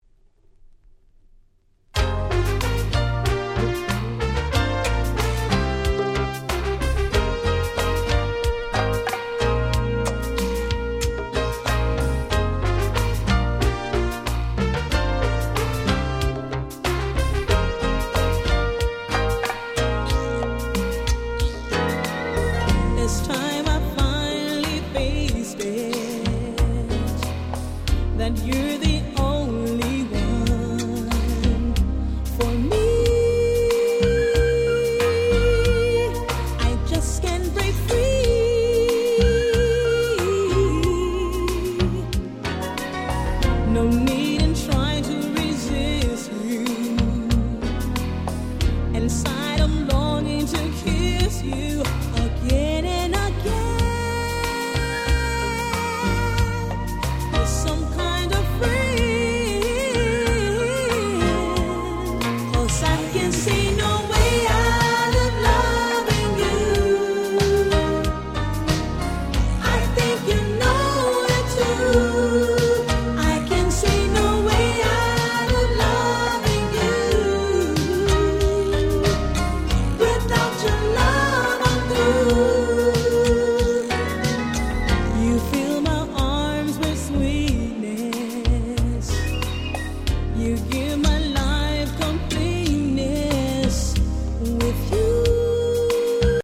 88' Super Nice UK R&B !!